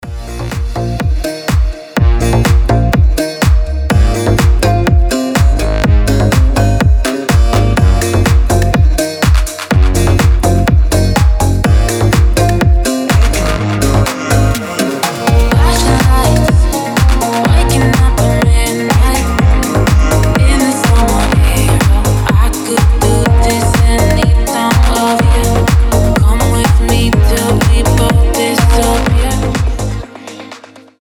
• Качество: 320, Stereo
deep house
басы
восточные
расслабляющие
Расслабляющая мелодия на восточный лад